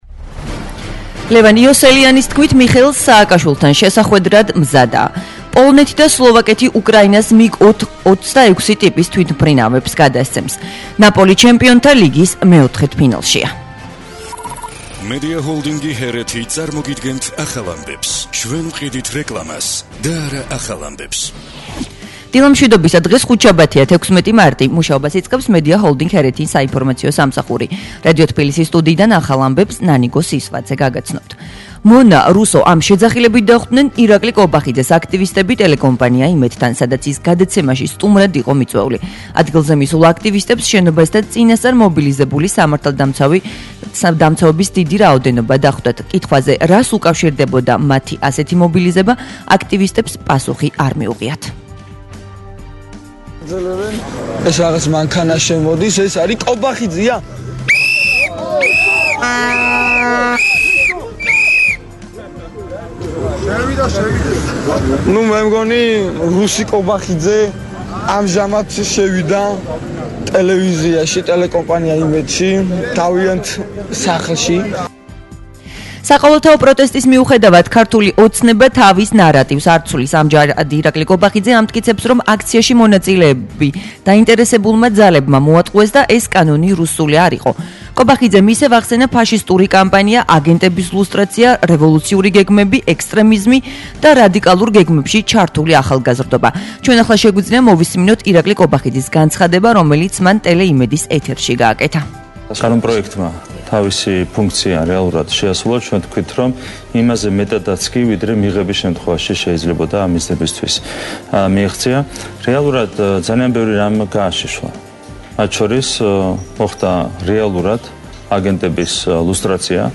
ახალი ამბები 09:00 საათზე